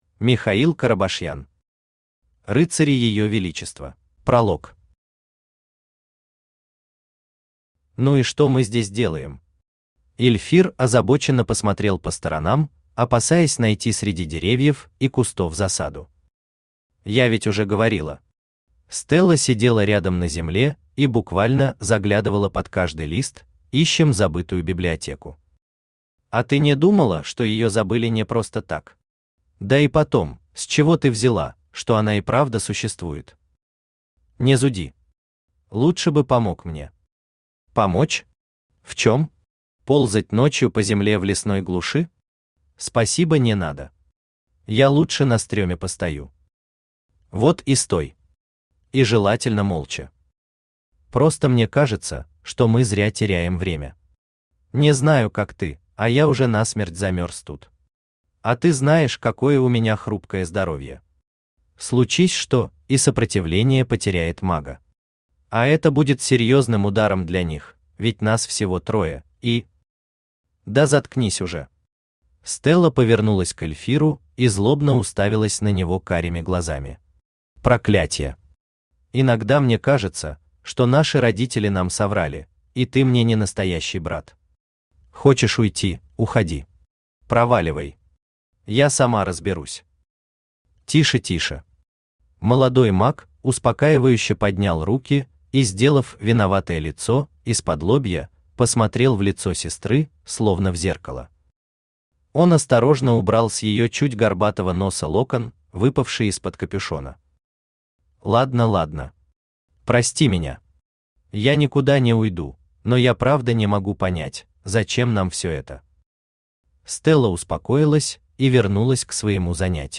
Аудиокнига Рыцари её величества | Библиотека аудиокниг
Aудиокнига Рыцари её величества Автор Михаил Семёнович Карабашьян Читает аудиокнигу Авточтец ЛитРес.